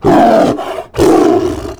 CosmicRageSounds / wav / general / combat / creatures / tiger / he / prepare1.wav